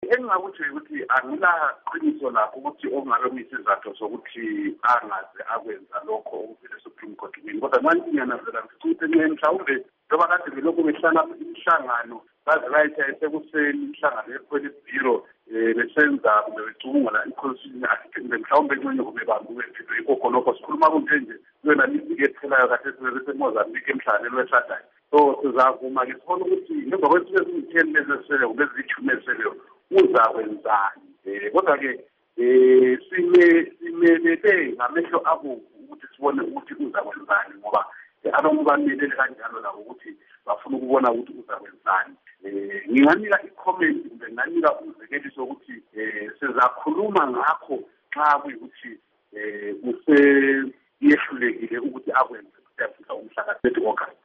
Ingxoxo LoMnu Abednico Bhebhe